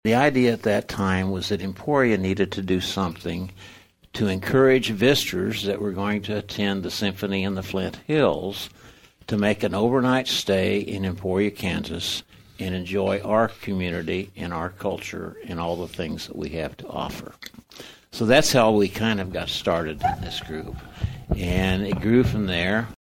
On KVOE’s On-Air Chat on Wednesday, organizers discussed how the group’s mission has changed over time.